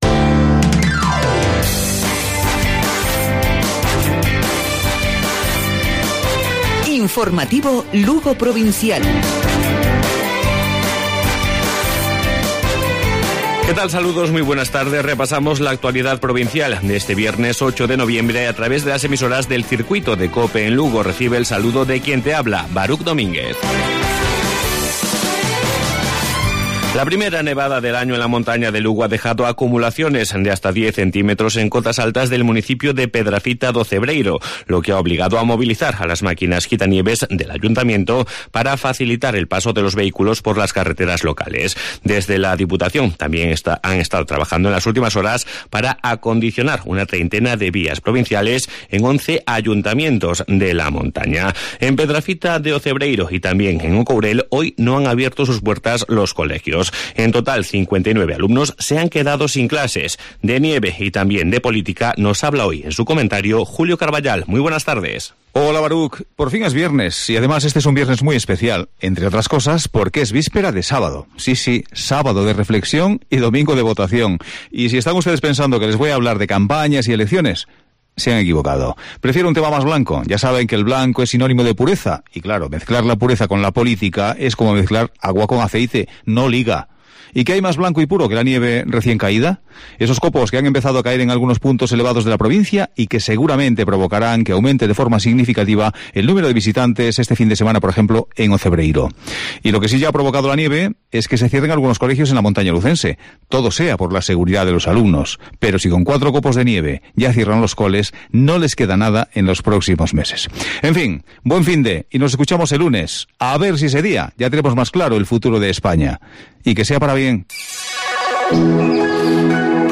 Informativo Provincial Cope.